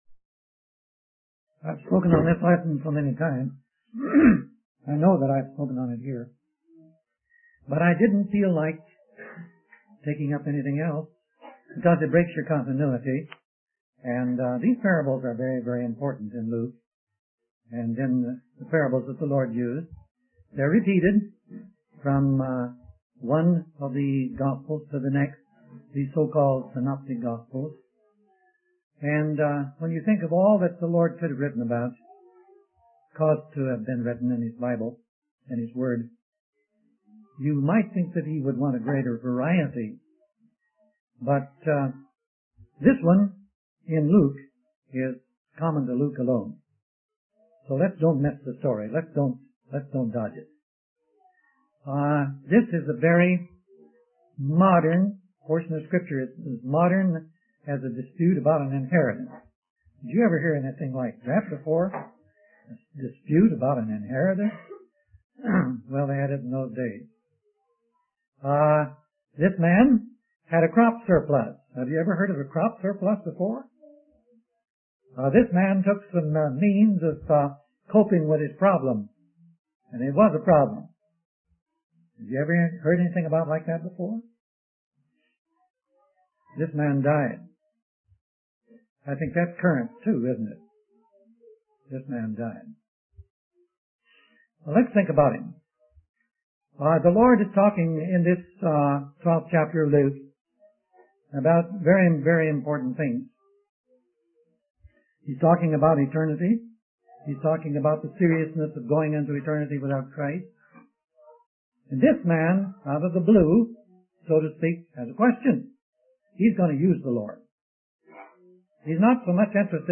In this sermon, the preacher focuses on the parable of the rich man who had a crop surplus.